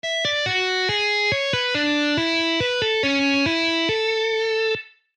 Pahoittelen kammottavaa tietokonesoundia.
Riffi 4